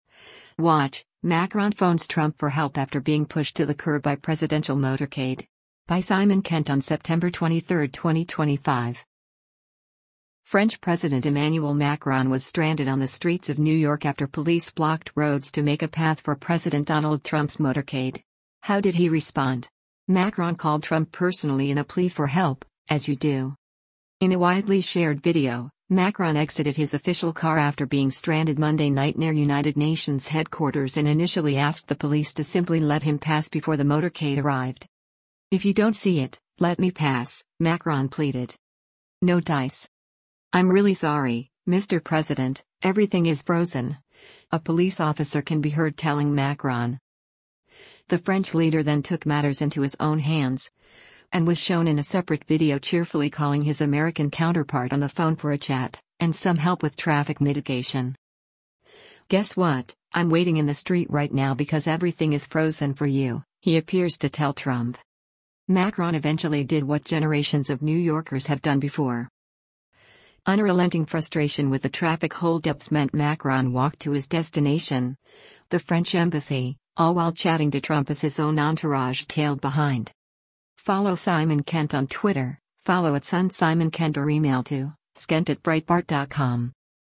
In a widely shared video, Macron exited his official car after being stranded Monday night near United Nations headquarters and initially asked the police to simply let him pass before the motorcade arrived.
“I’m really sorry, Mr President, everything is frozen,” a police officer can be heard telling Macron.
The French leader then took matters into his own hands, and was shown in a separate video cheerfully calling his American counterpart on the phone for a chat – and some help with traffic mitigation.